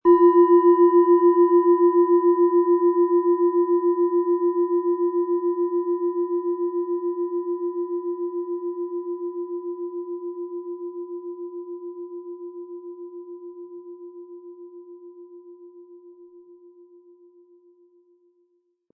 Diese tibetische Planetenschale Platonisches Jahr ist von Hand gearbeitet.
Um den Originalton der Schale anzuhören, gehen Sie bitte zu unserer Klangaufnahme unter dem Produktbild.
PlanetentonPlatonisches Jahr
MaterialBronze